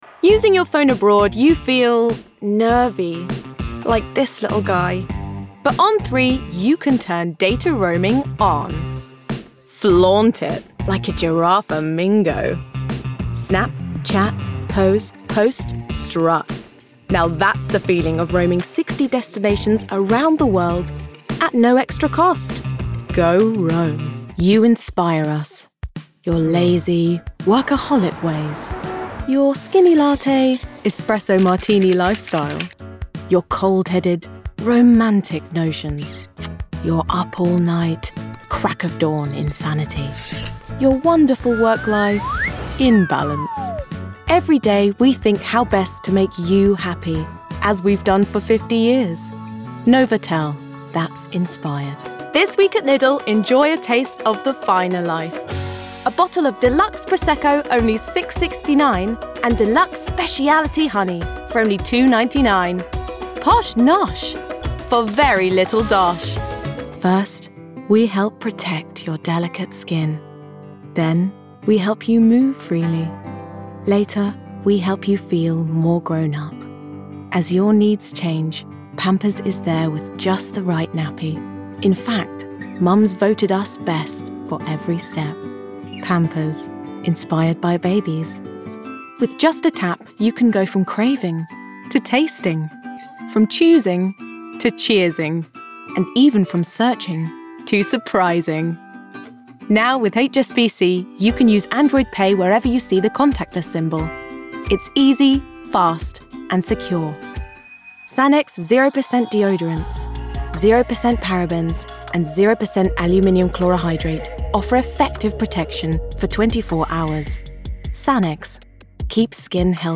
Voice Reel
Commercial Reel